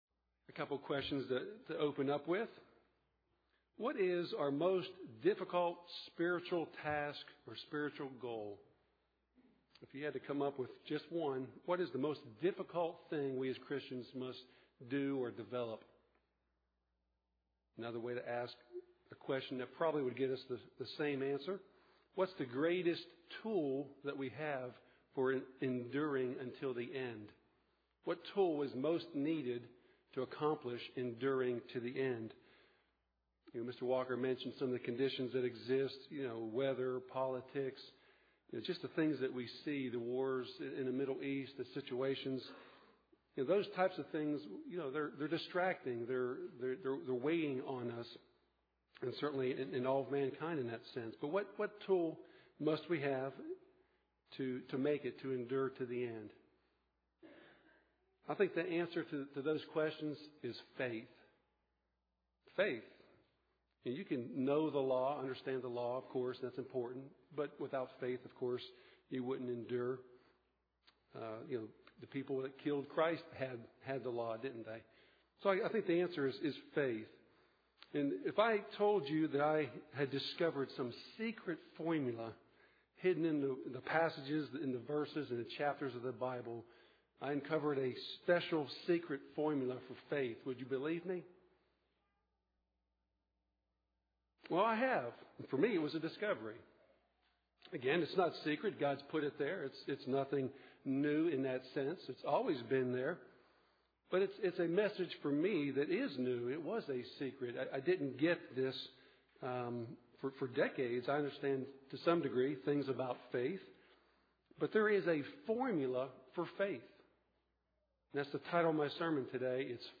UCG Sermon Studying the bible?